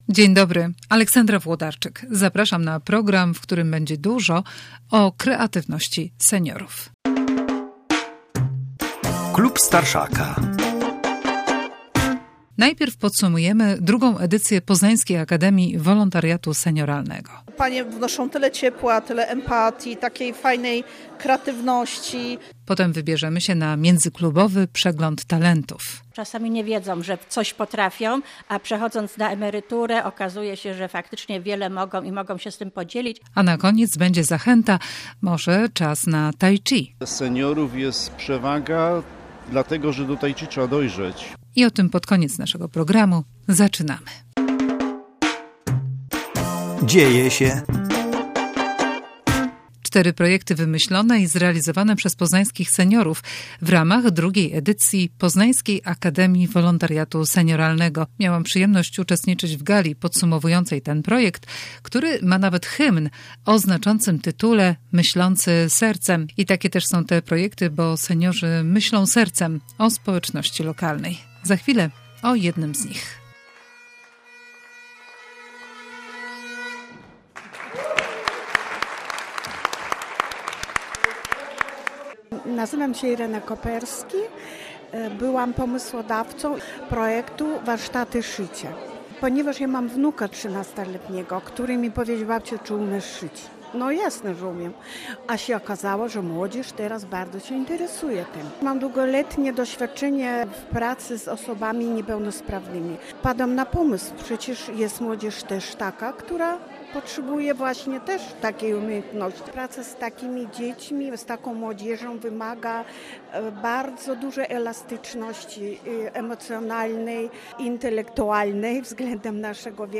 Potem relacja z Międzyklubowego Przeglądu Talentów, zorganizowanego w Centrum Profilaktyki Świt, podczas którego zaprezentowali się seniorzy z filii Zespołów Dziennych Domów Pomocy w Poznaniu. Na koniec o zaletach tai chi, bo do tej formy aktywności "trzeba dojrzeć" - i o tym opowie nam instruktor z Poznańskiego Stowarzyszenia Tai chi.